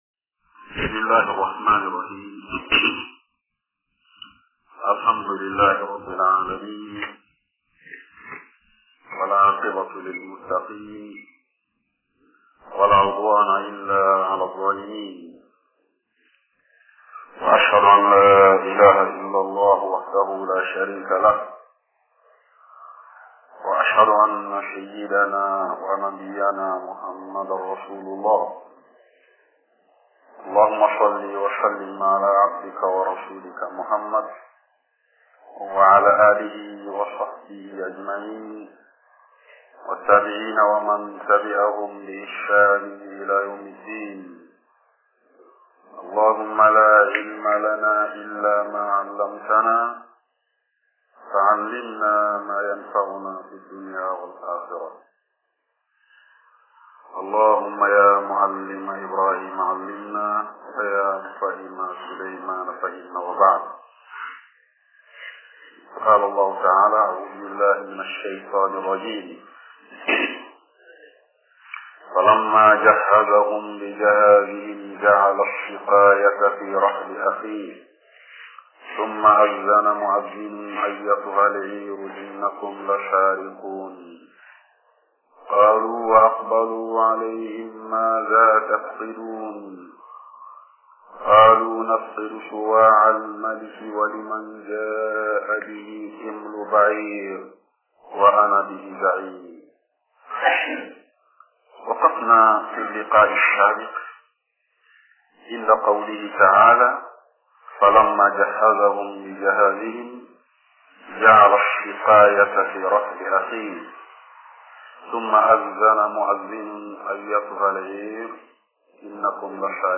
Accueil > Tafsir du Saint Coran en bambara > 012 - Yusuf